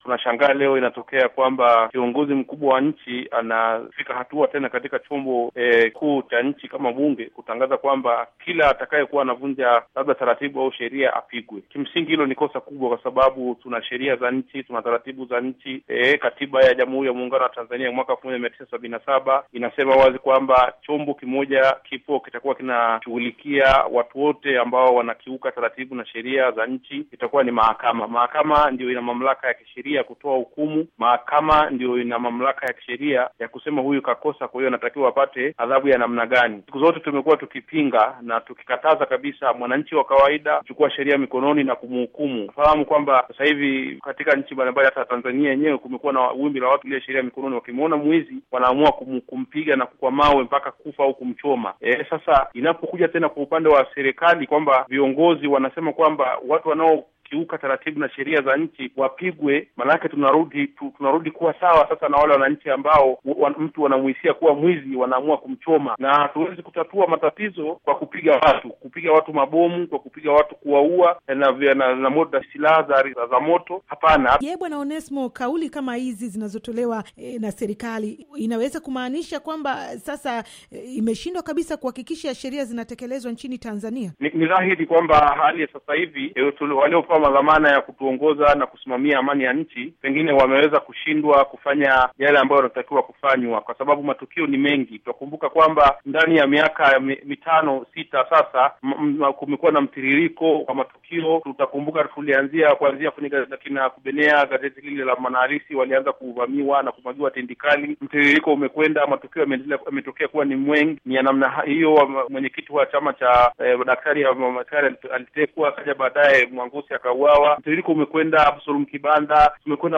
Mahojiano